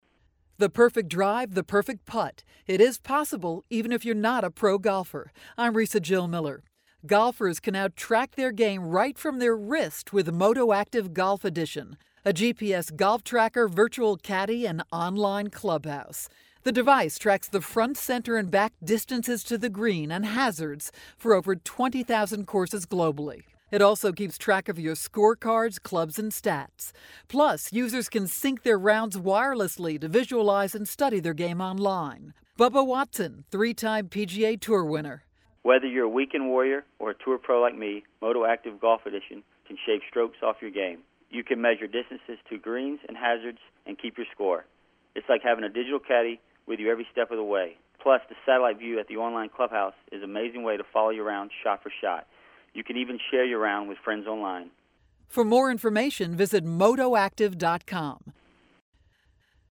April 4, 2012Posted in: Audio News Release